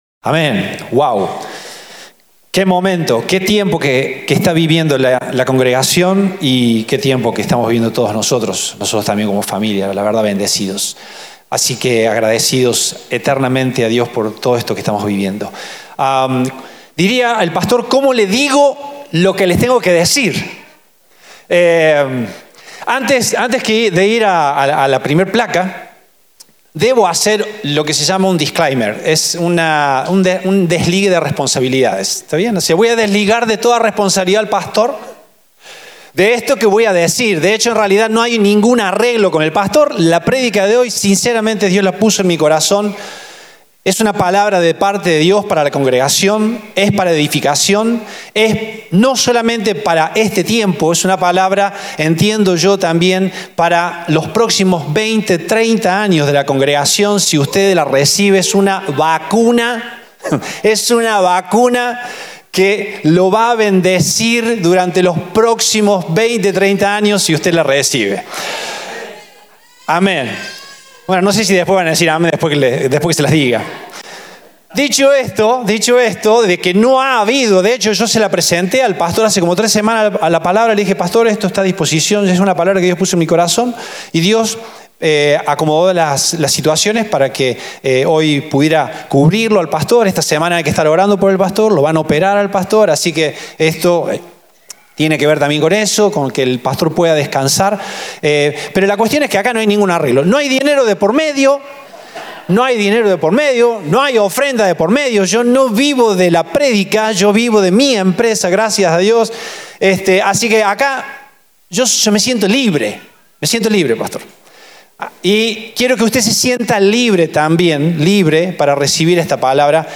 Compartimos el mensaje del Domingo 29 de Setiembre de 2024